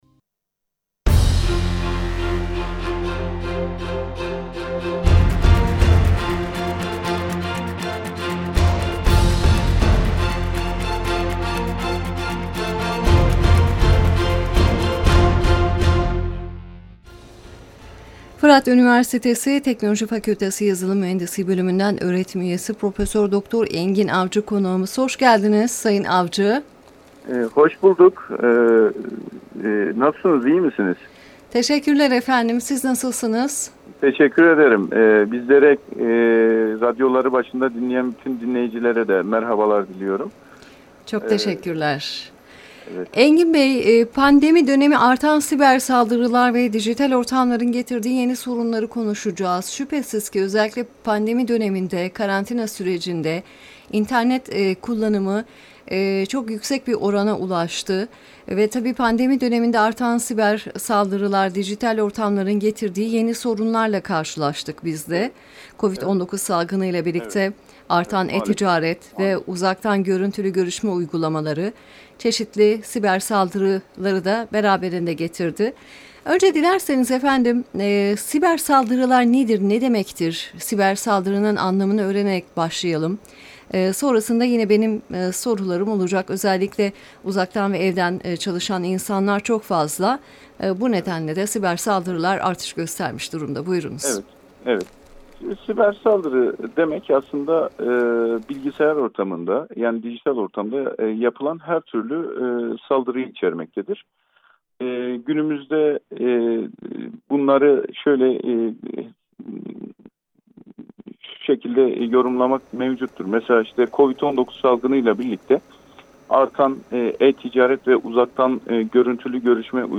canlı yayın